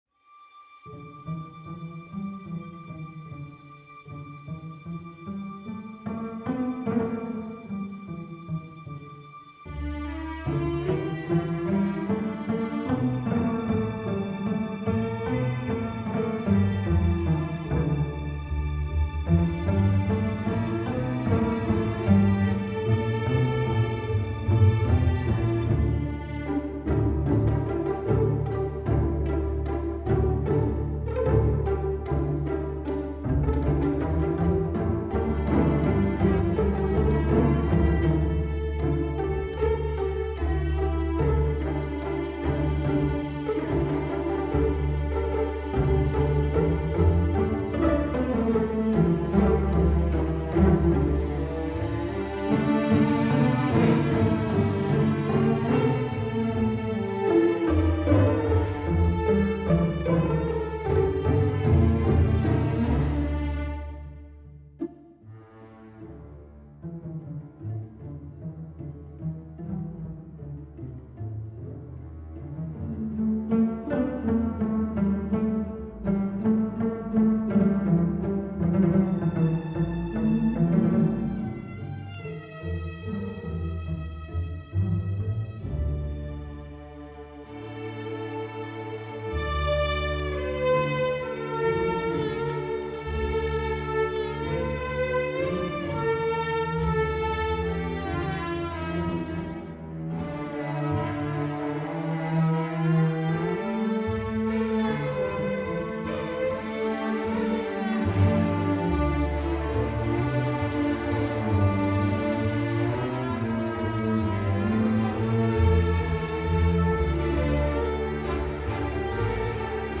Red Mountains and Golden Silk - Orchestral and Large Ensemble - Young Composers Music Forum